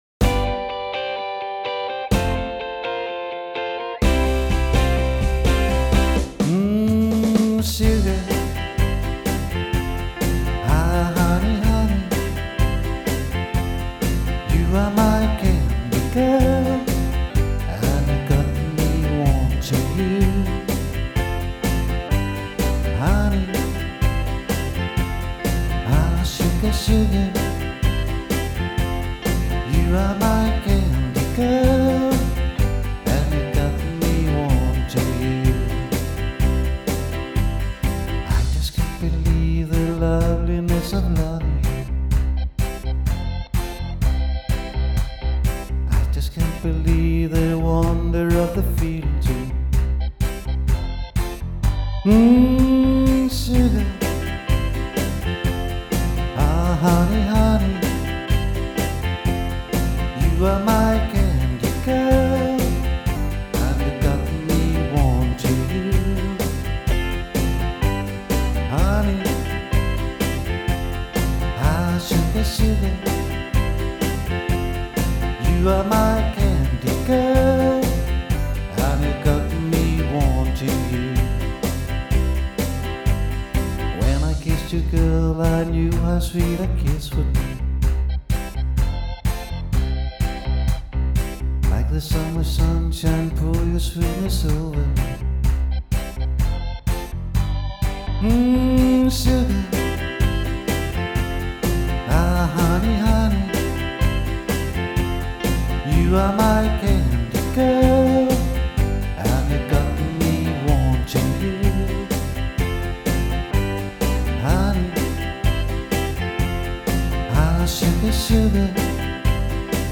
• Solomusiker